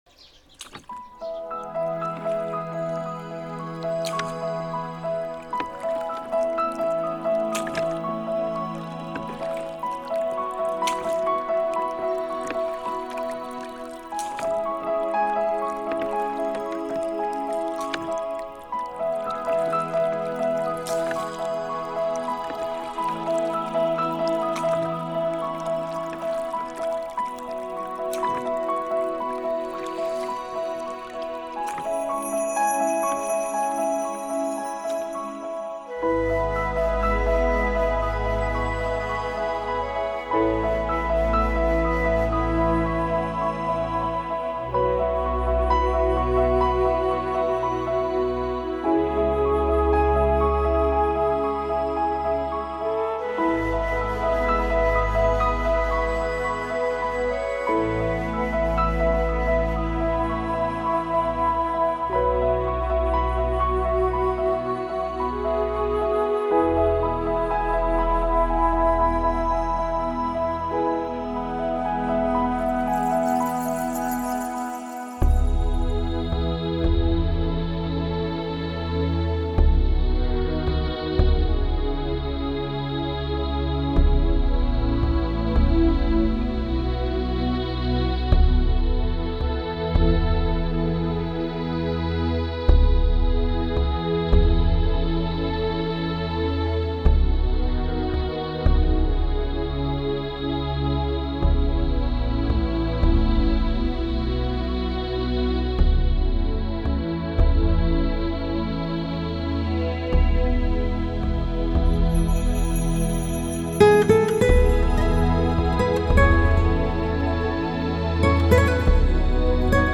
Жанр: Relax